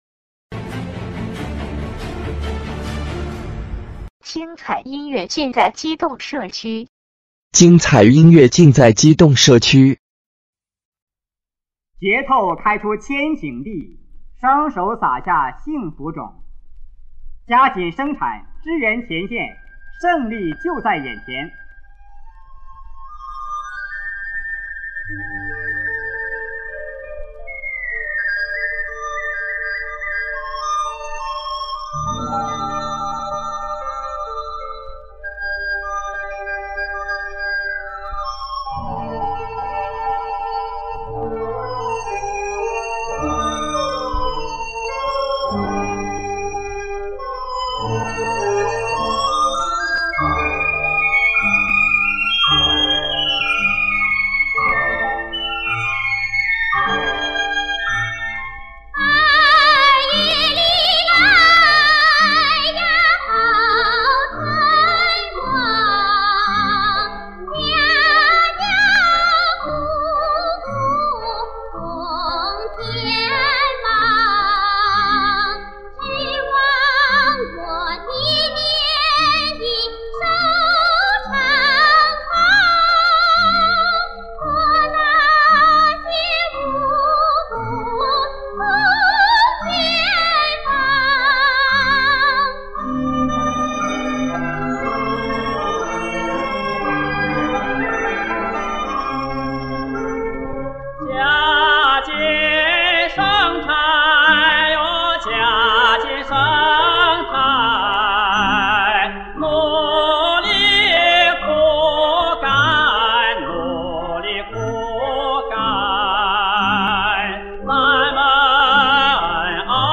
黑胶唱片
1965年录音出版
对唱
领唱 合唱